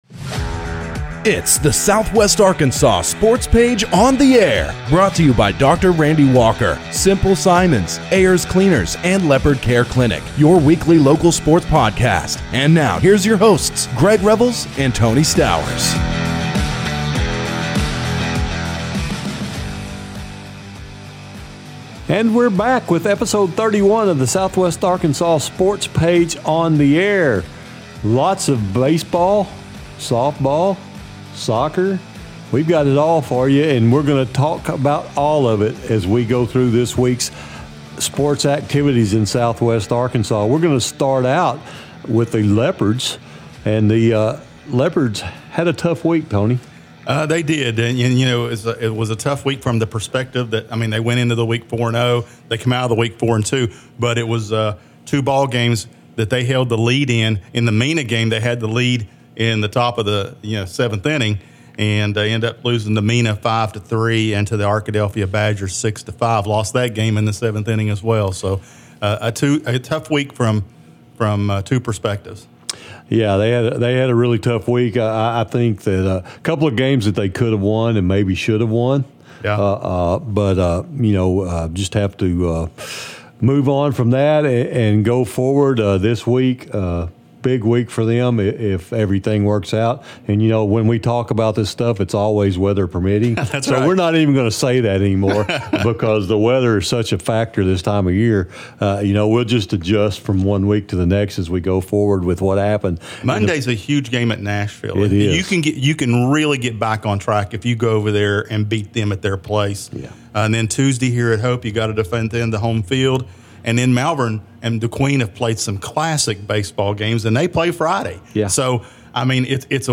in studio.